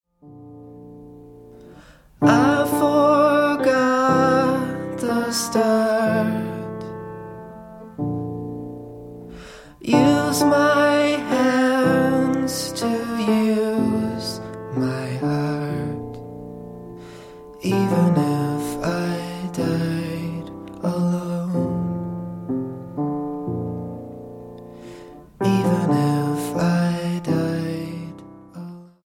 STYLE: Roots/Acoustic
Piano, banjo and trumpet
with its piano and trumpet interplay